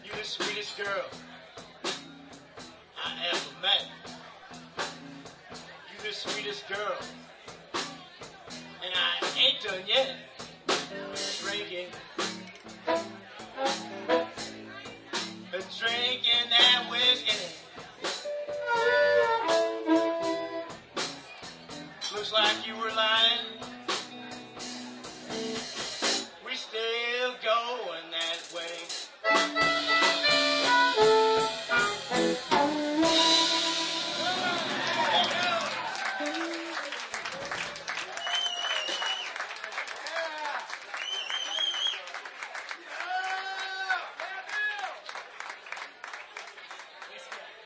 5th OTS Recital - Winter 2005 - rjt_4118